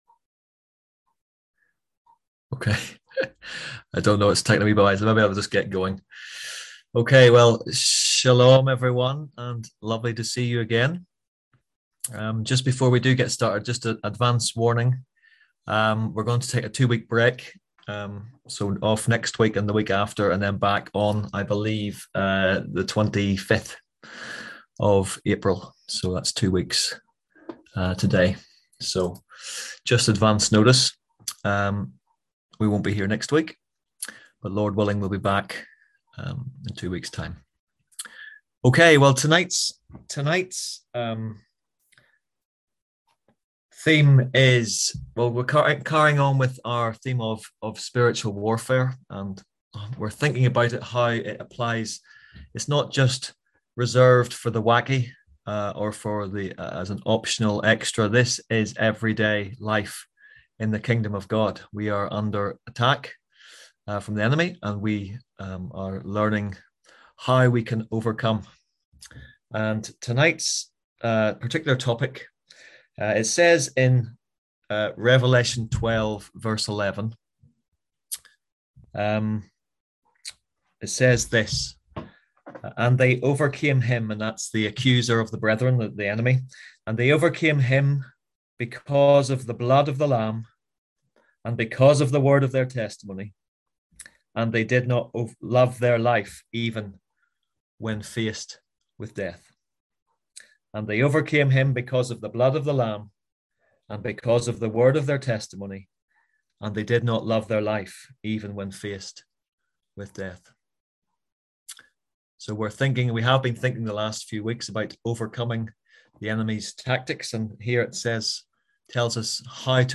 Click here to listen to the full story of our F14 conference, ‘when Christians disagree’.